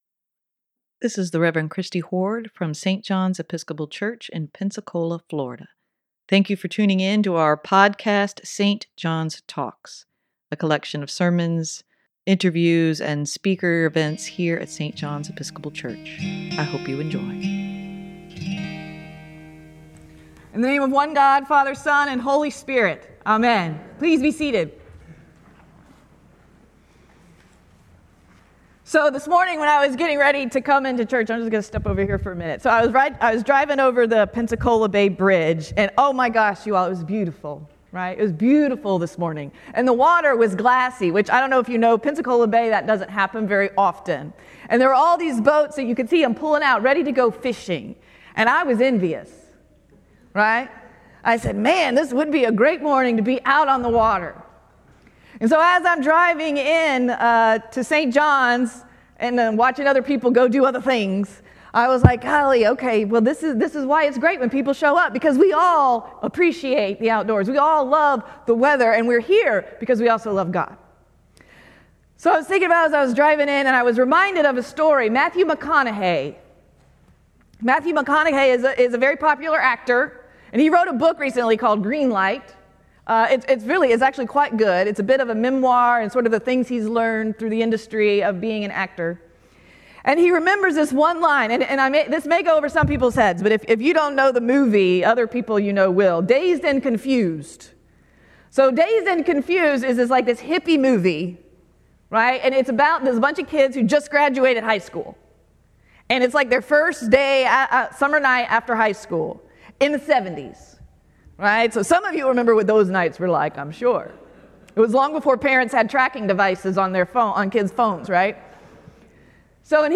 Sermon for April 14, 2024: Spread the beautiful truth of joy and love